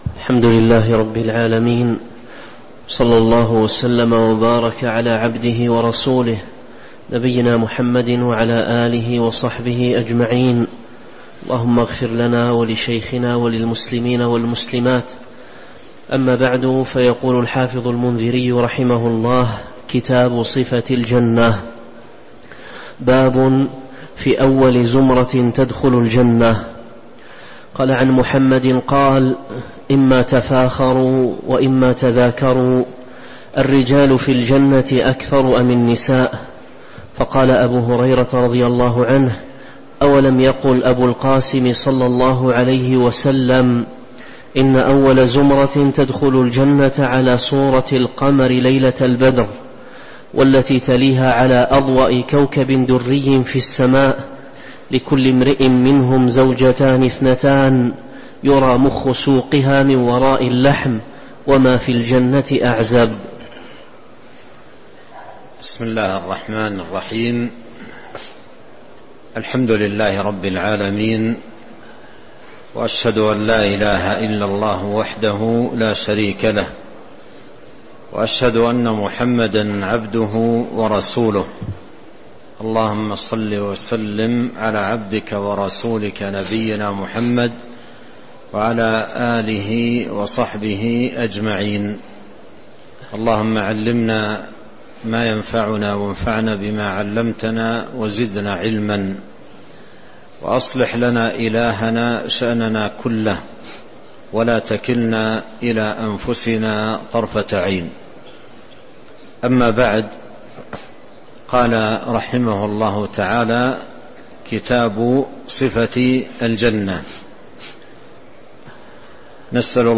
تاريخ النشر ٢٣ ذو الحجة ١٤٤٣ هـ المكان: المسجد النبوي الشيخ